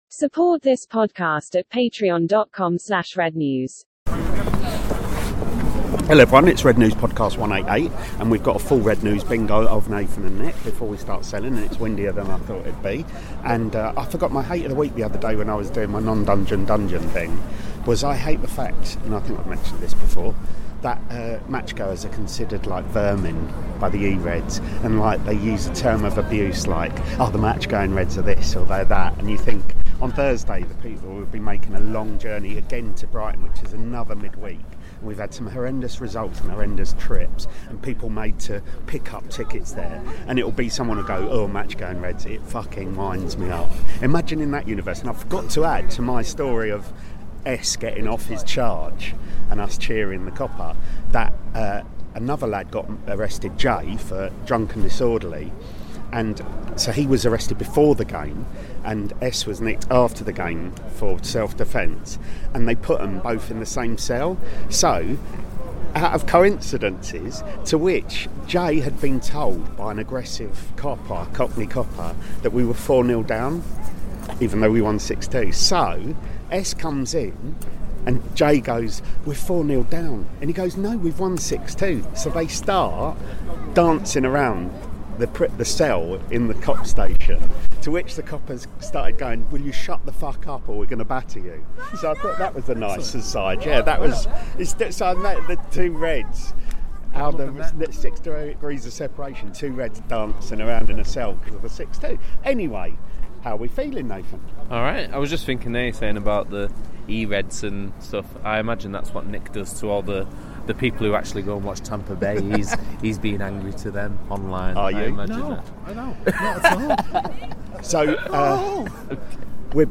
The independent, satirical Manchester United supporters' fanzine - for adults only, contains expletives, talks MUFC, or not at times, as we're back selling before the game, and in various pubs after it.